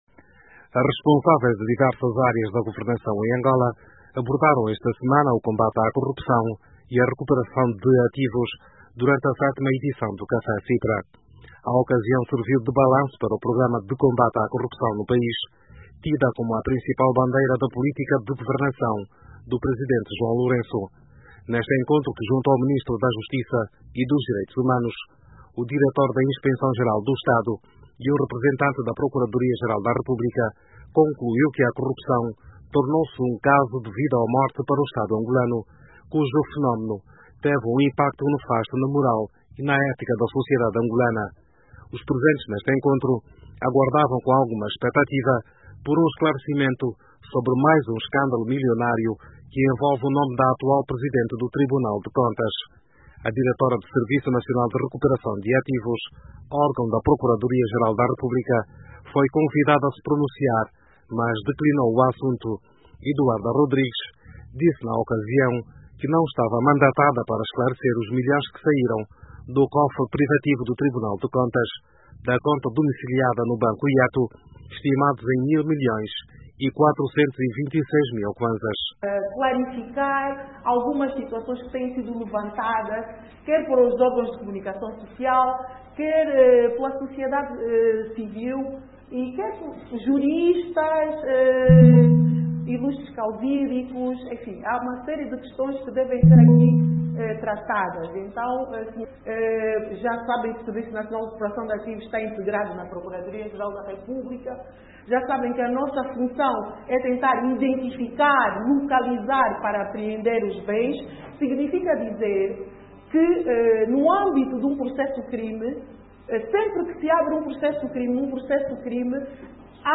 Analista político e jurista analisam o real empenho do Governo e o longo caminho para a recuperação desse dinheiro.